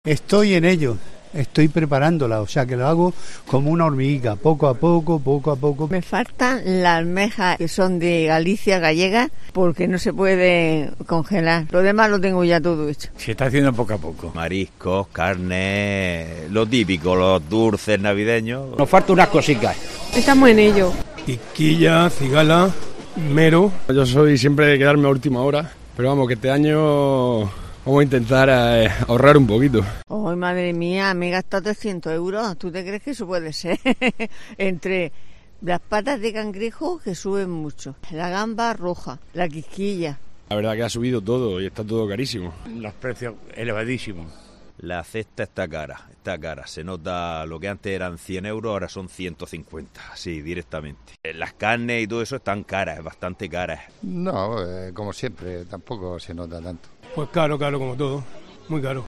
Consumidores en el mercado de Verónicas